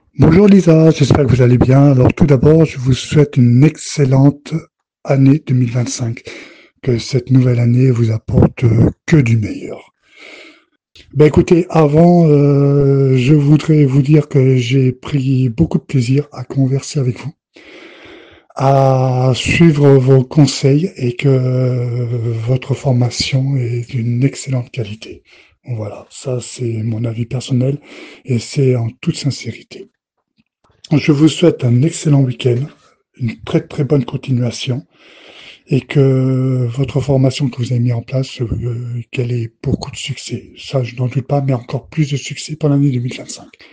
Enfin un témoignage audio